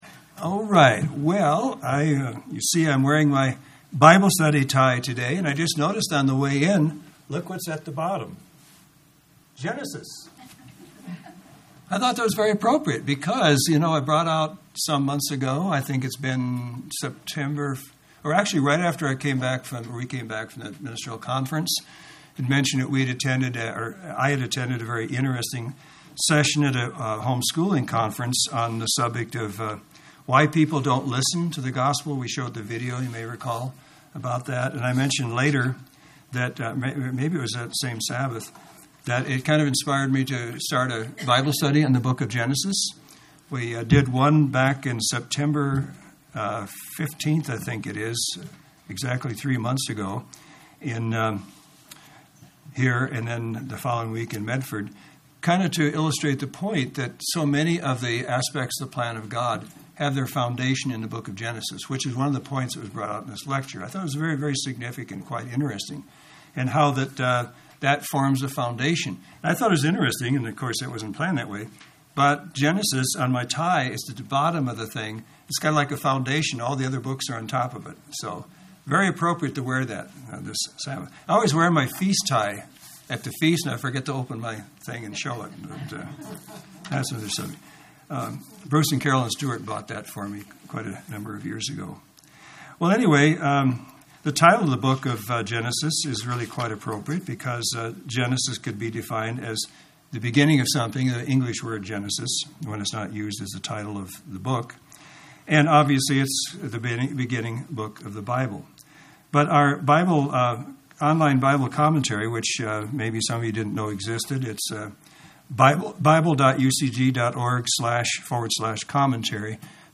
Given in Central Oregon
UCG Sermon Studying the bible?